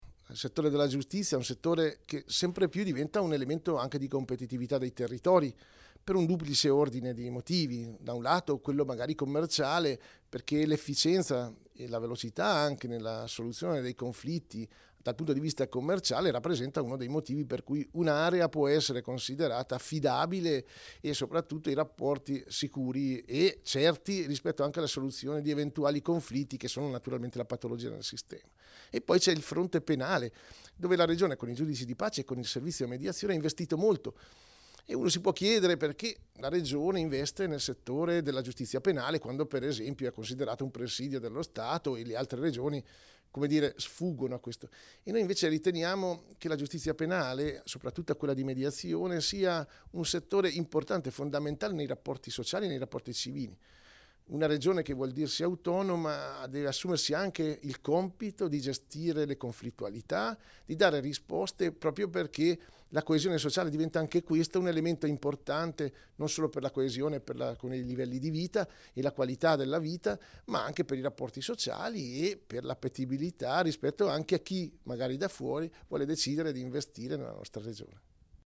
Un convegno di studio oggi a Trento
Allegato audio: intervista all'Assessore Detomas -